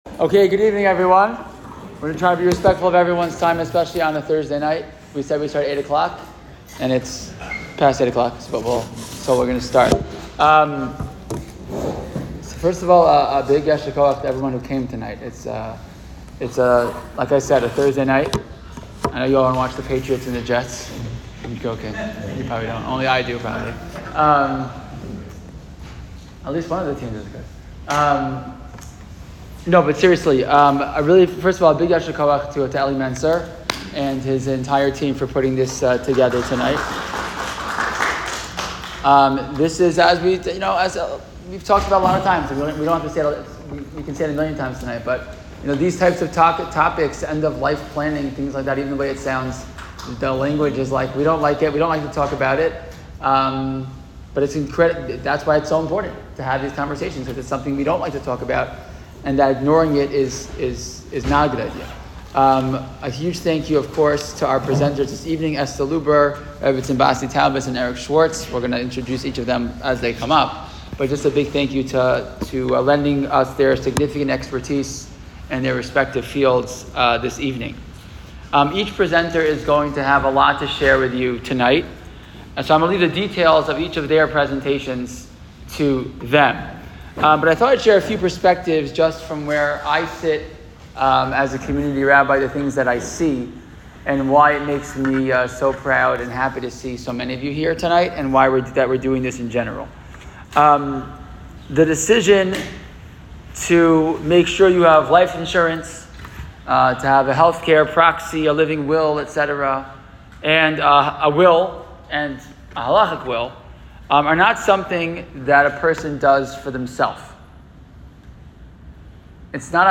What's Your Plan? A conversation about responsible planning for your family's future